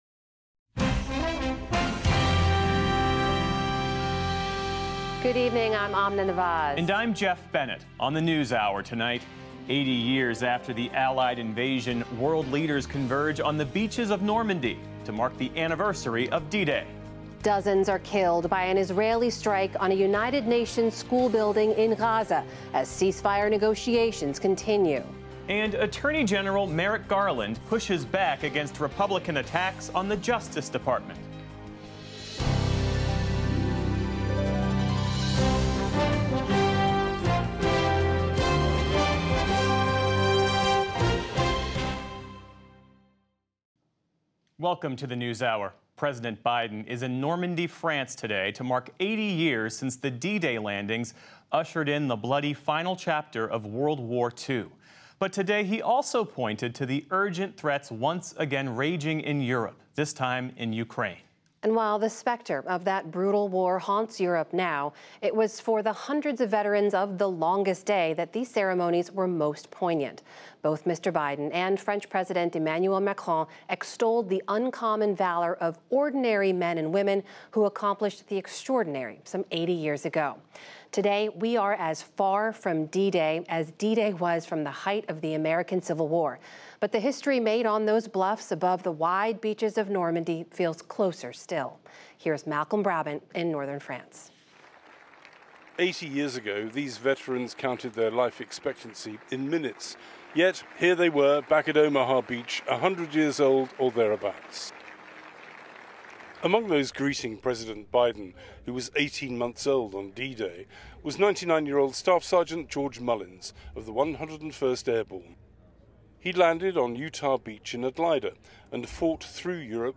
June 6, 2024 - PBS NewsHour full episode